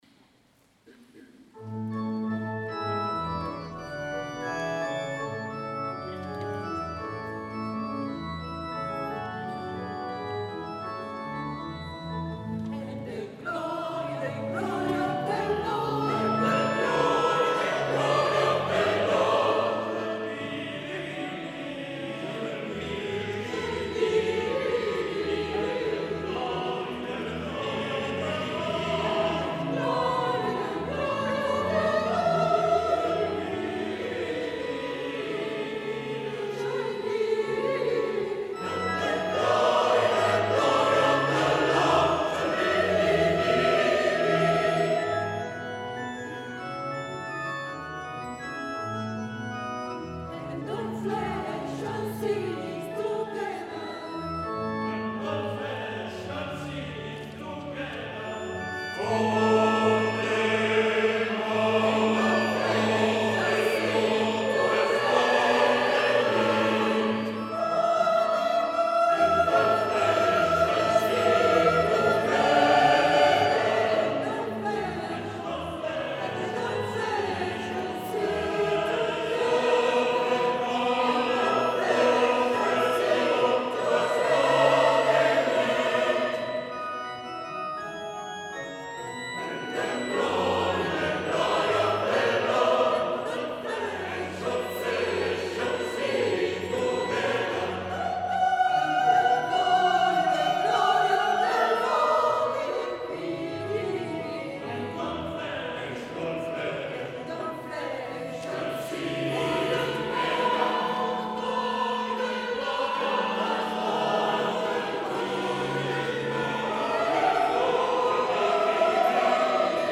S. Gaudenzio church choir Gambolo' (PV) Italy
Il Concerto di Natale 2025
audio del concerto